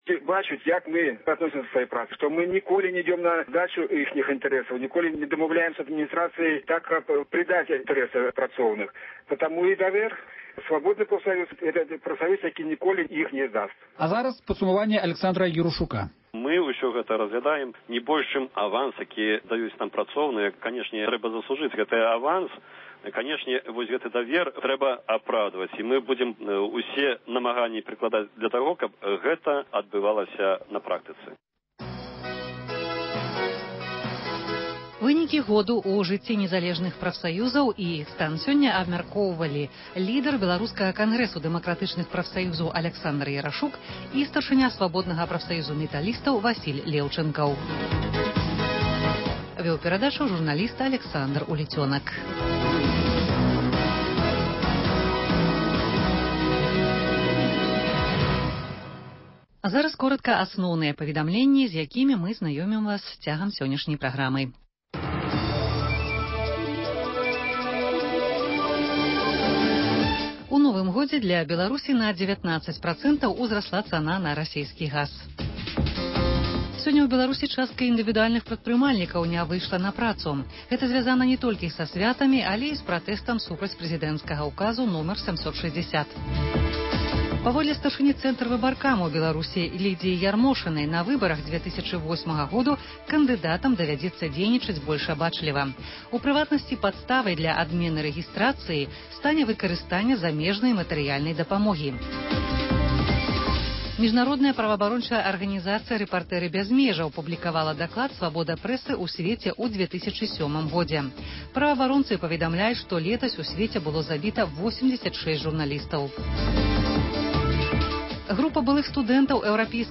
Званкі на “Свабоду”: народ пра Лукашэнку і чыноўнікаў.